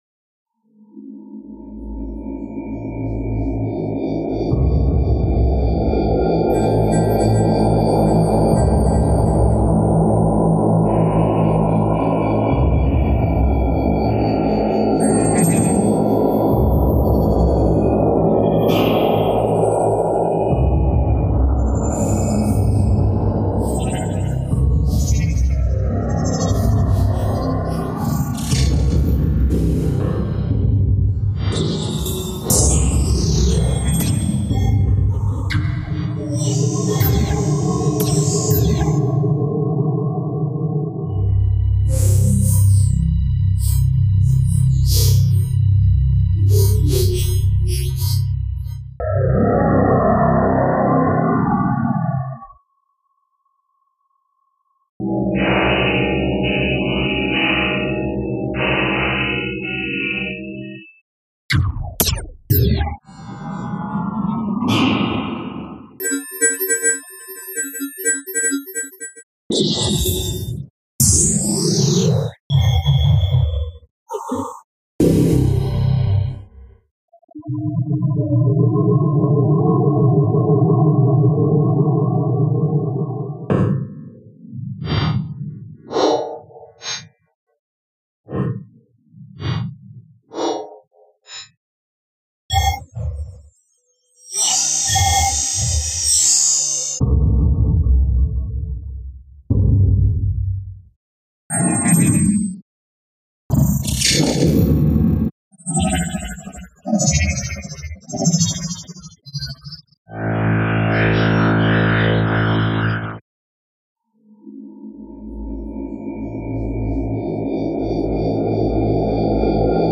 Video Game FXs
Video Game FXs.mp3